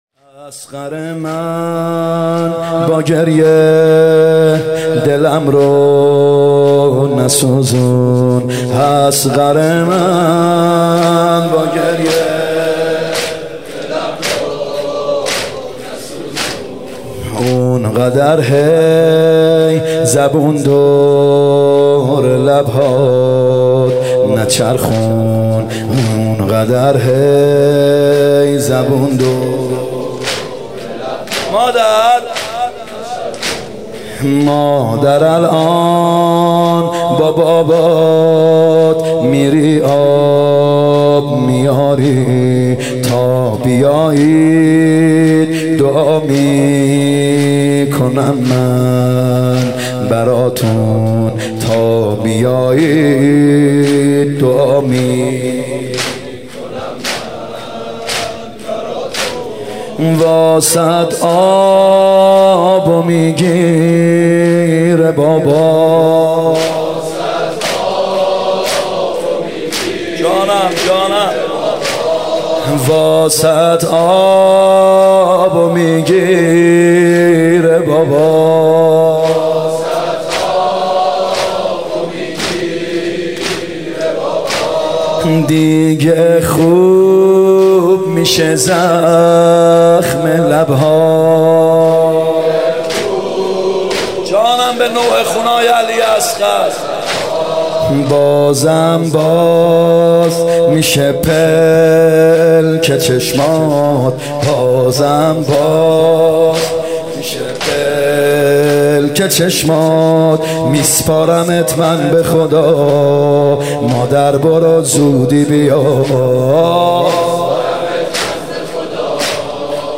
محرم 95(هیات یا مهدی عج)